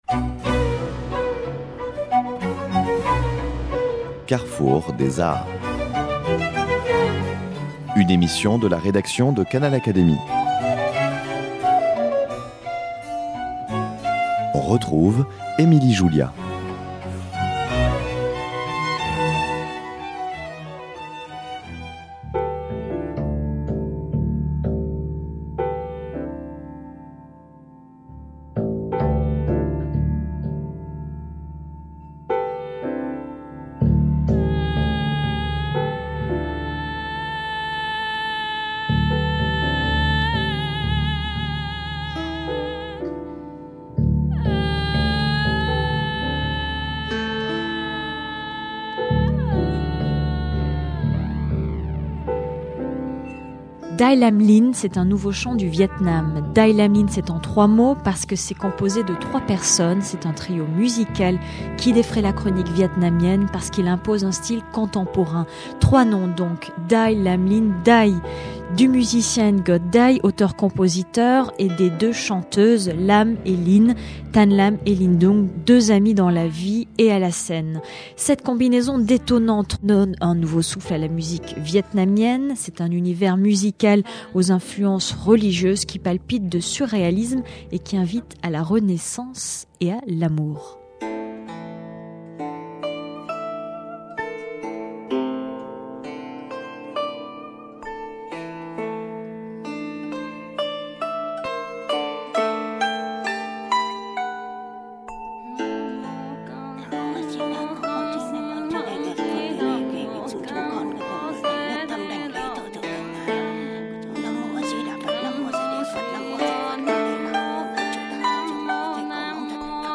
Les voix étranges montent très haut pour redescendre brusquement. La musique atonale commence sur des airs confus. Les paroles parlent, murmurent, gueulent.